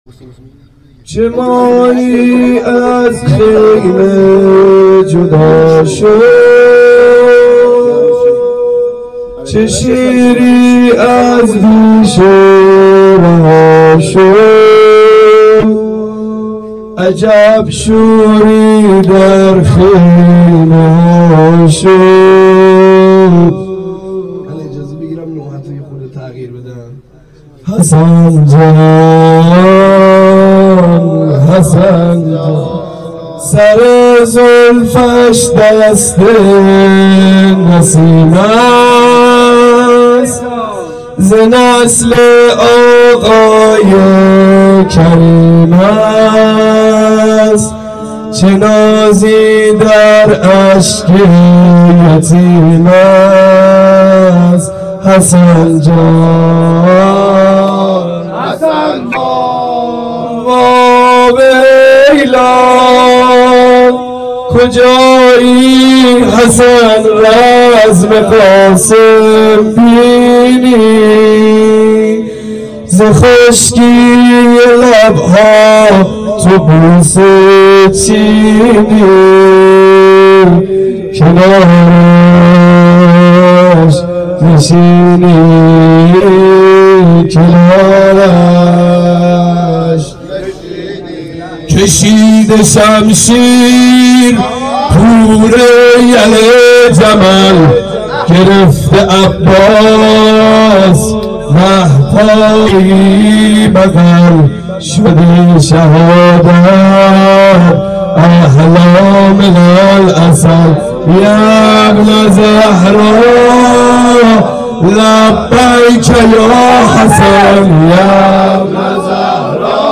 4-nohe.mp3